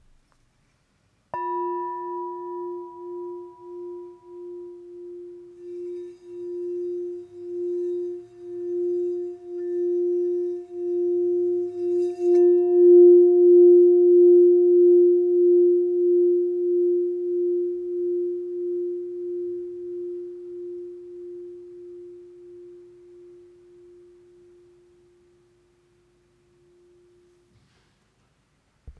F Note Flower of Life Singing Bowl